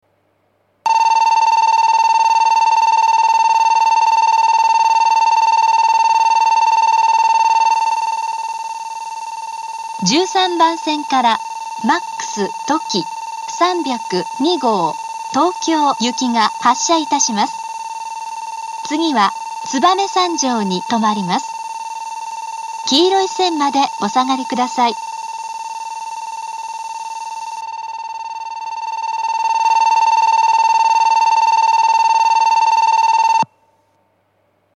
２面４線のホームで、全ホームで同じ発車ベルが流れます。
１３番線発車ベル Ｍａｘとき３０２号東京行の放送です。